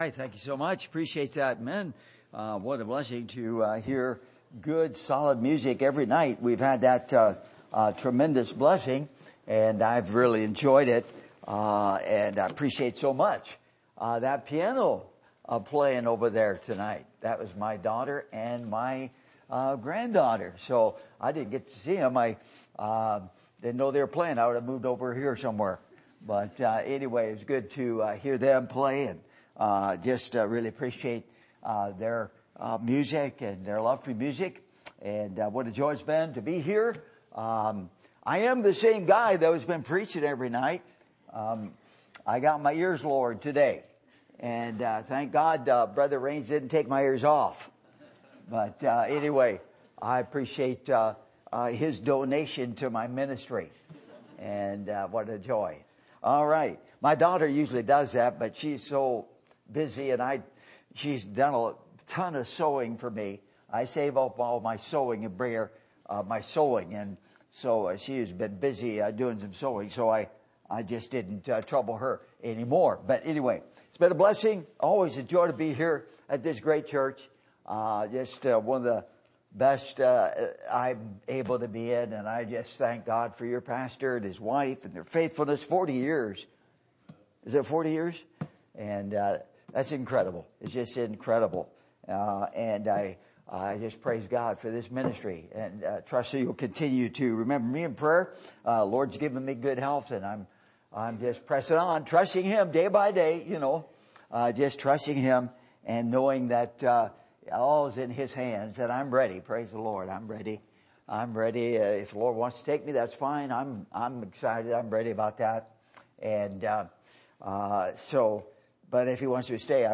Service Type: Revival Meetings